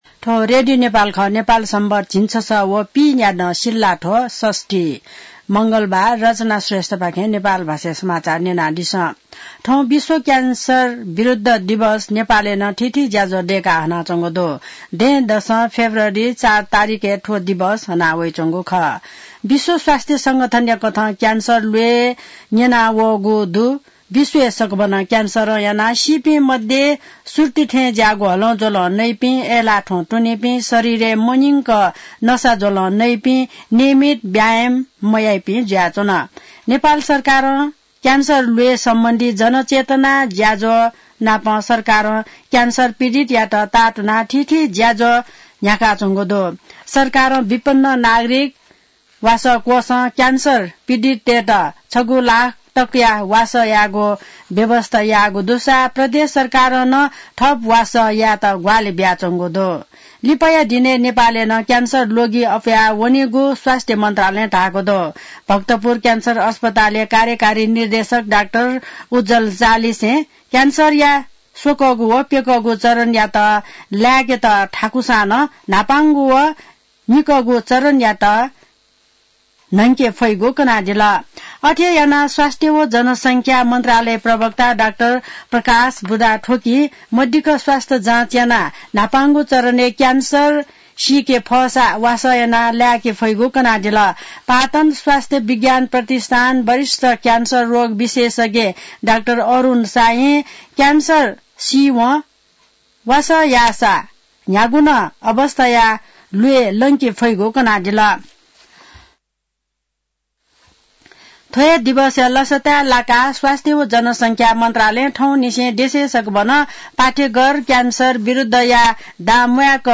नेपाल भाषामा समाचार : २३ माघ , २०८१